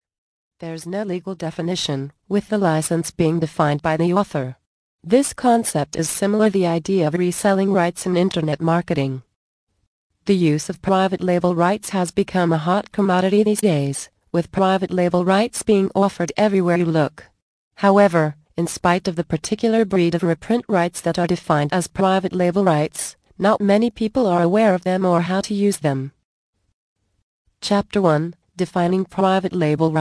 Cashing in on the Uses of Private Label Rights audio book